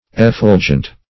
effulgent - definition of effulgent - synonyms, pronunciation, spelling from Free Dictionary
Effulgent \Ef*ful"gent\, a. [L. effulgens, -entis, p. pr. of